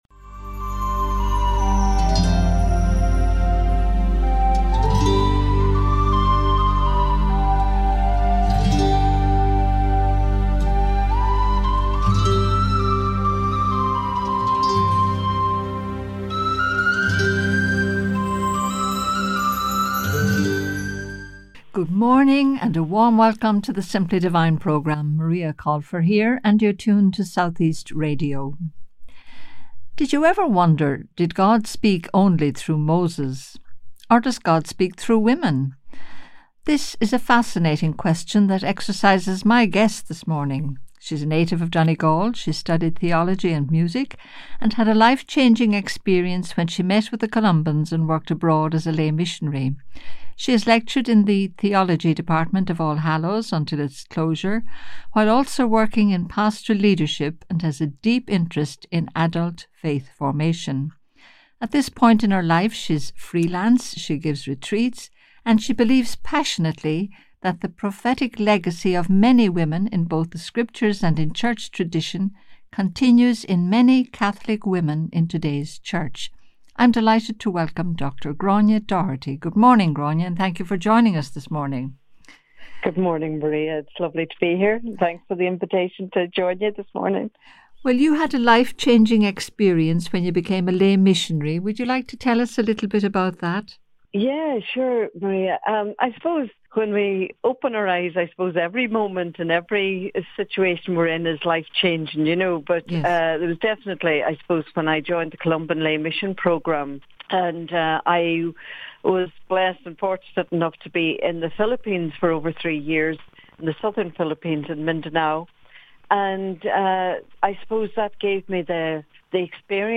An Interview
This interview runs for about 25 minutes.